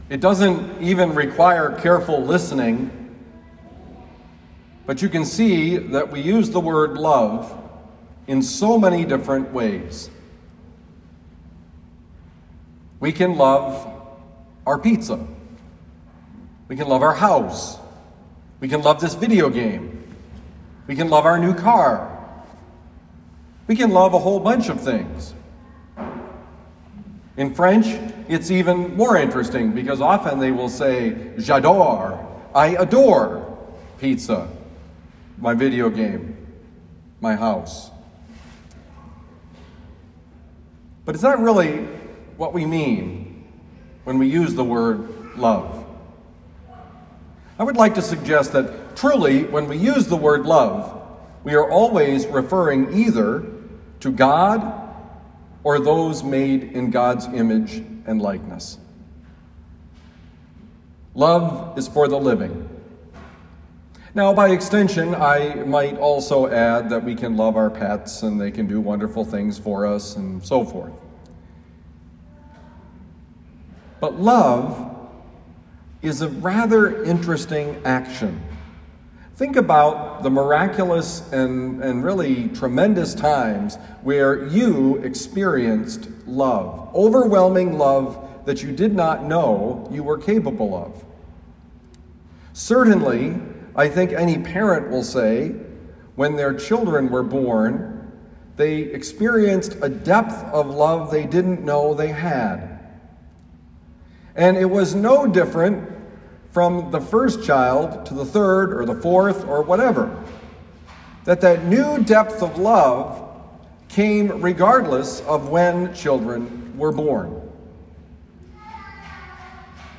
Homily for Sunday, April 25, 2021
Given at Our Lady of Lourdes Parish, University City, Missouri.